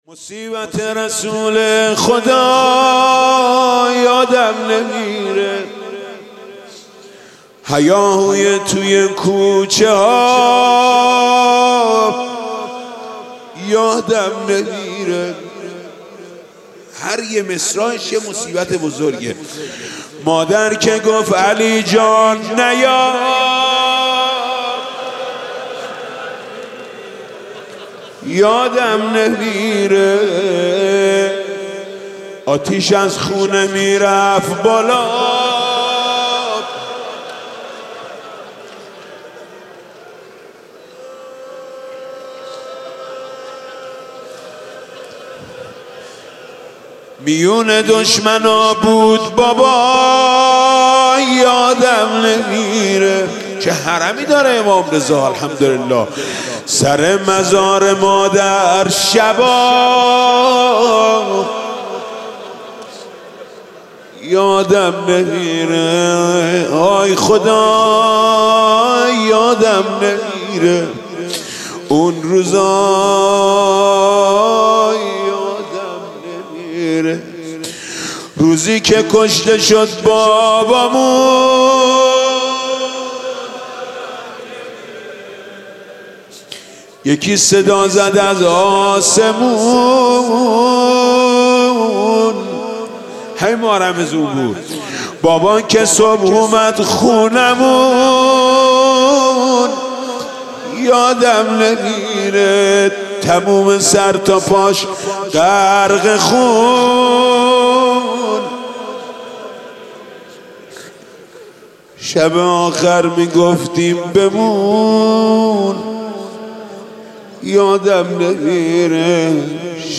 مناسبت : شب سوم رمضان
مداح : محمود کریمی قالب : روضه